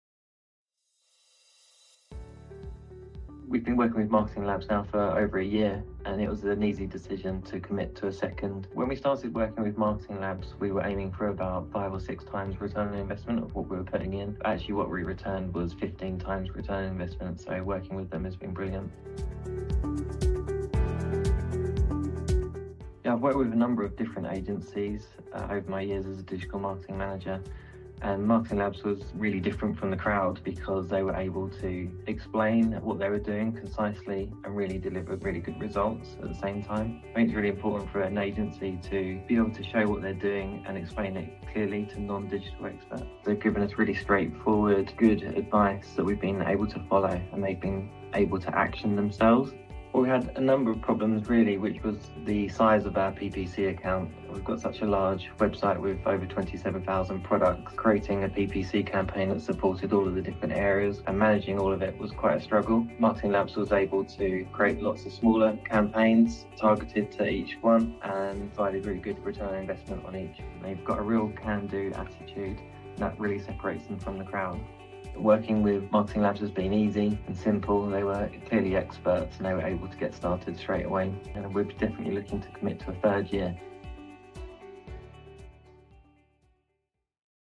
DD-Group-Testimonial.mp3